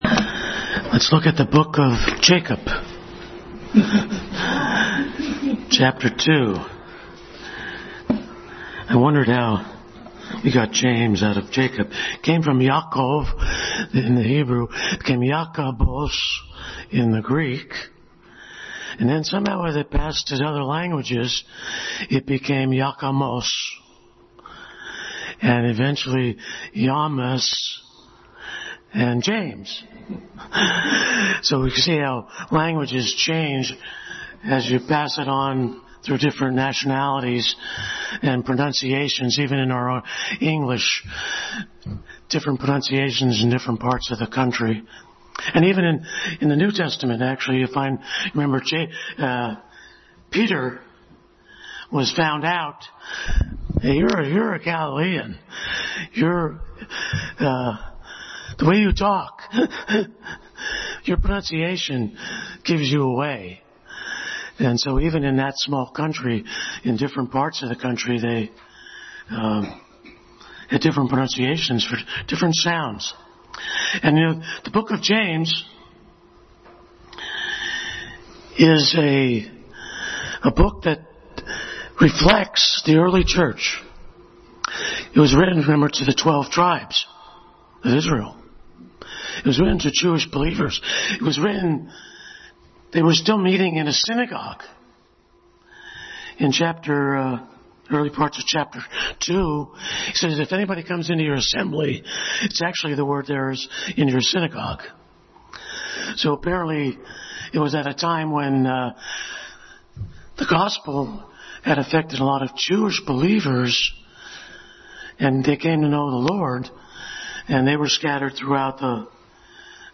James 2:14-26 Passage: James 2:14-26, Ephesians 2:1-10, Romans 4:1-5 Service Type: Sunday School